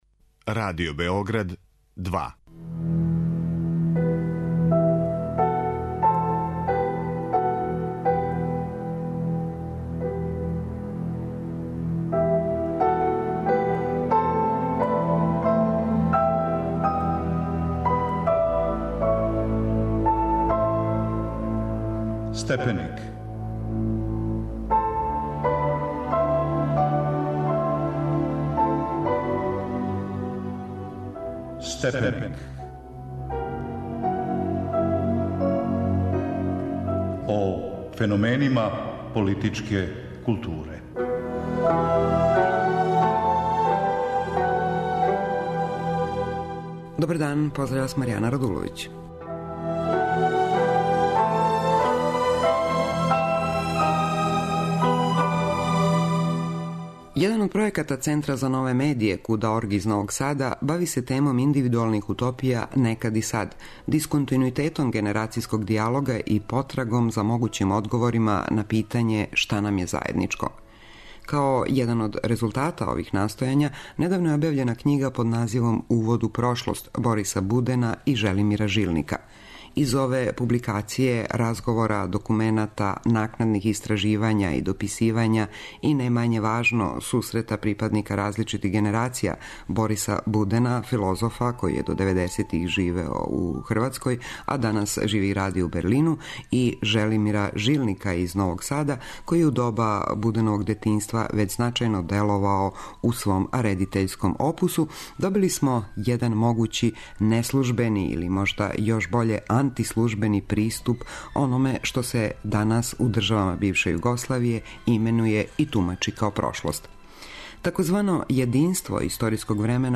У емисији говоре: Борис Буден, филозоф из Берлина и Желимир Жилник, филмски редитељ из Новог Сада.